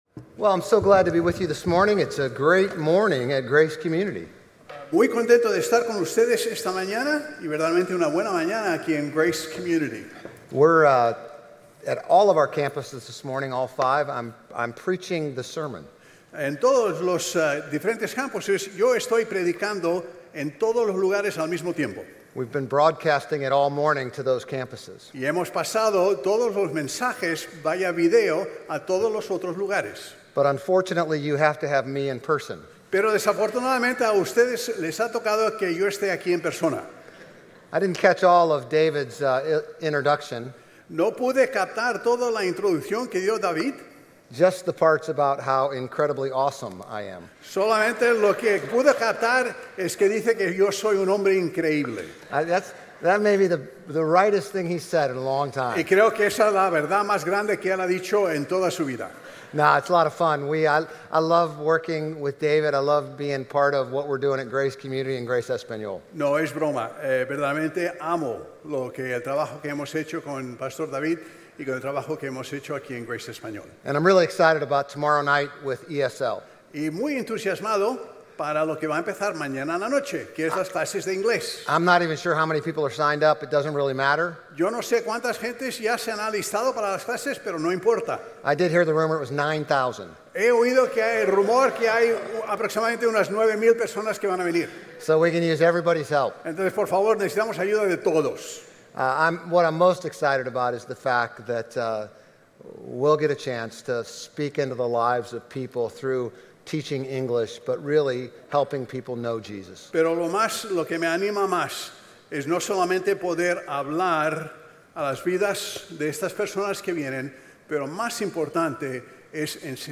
Sermones Grace Español 9_7 Grace Espanol Campus Sep 08 2025 | 00:28:51 1x 00:00 / 00:28:51 Subscribe Share RSS Feed Share Link Embed